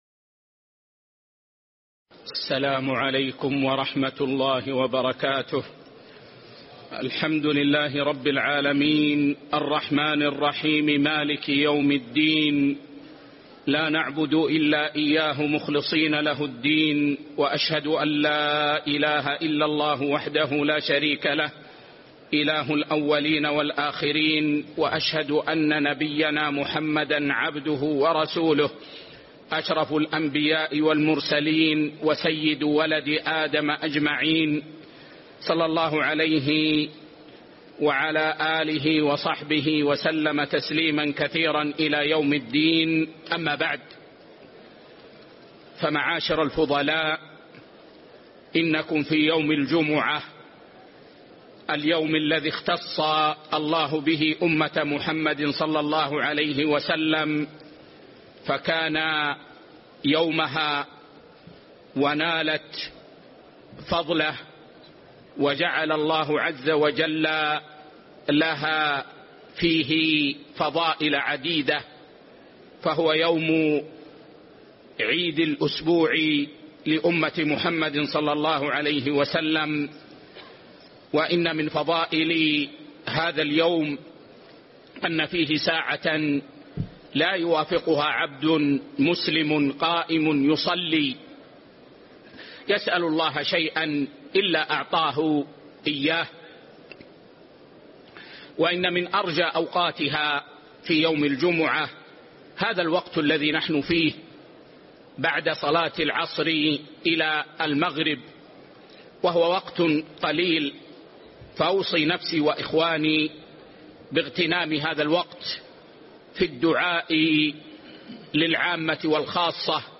شرح صحيح الترغيب والترهيب 1 الدرس 103